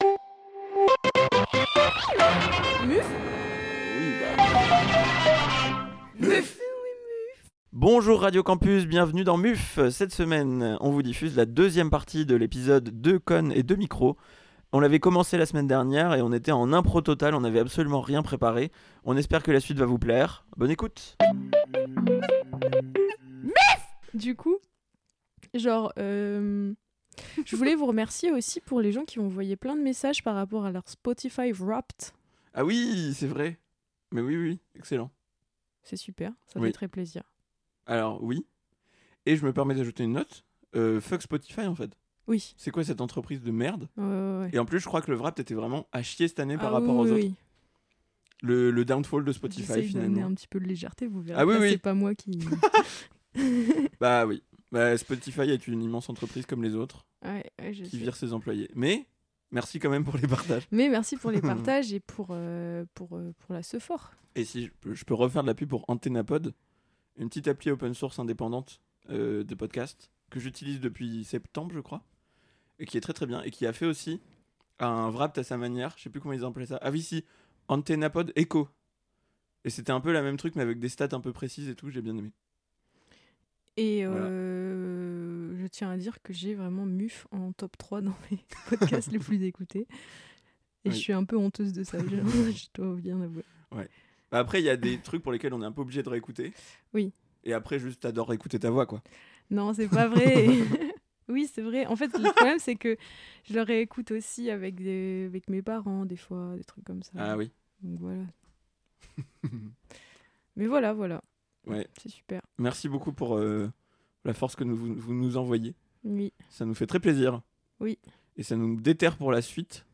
Voici la suite de notre discussion en improvisation totale ! On y parle entre autres de pâtes alphabet, de Gaston Lagaffe, et on fouille dans nos notes de téléphone.